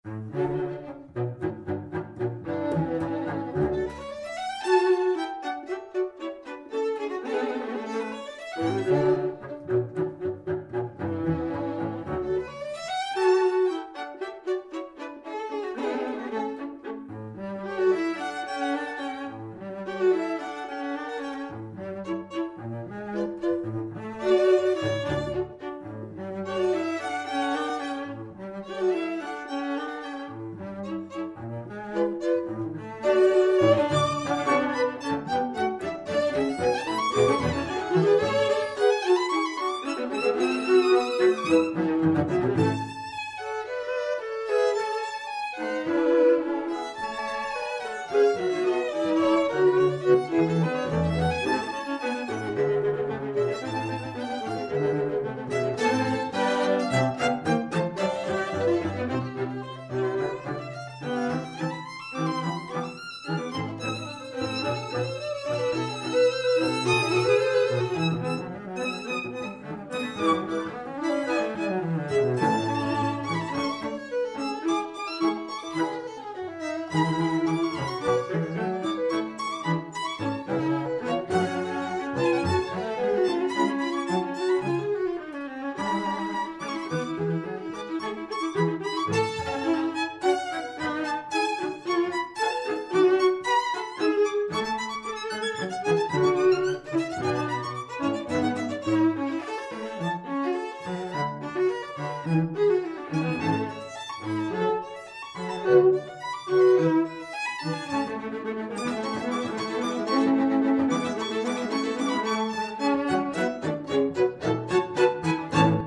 Soundbite 1st Movt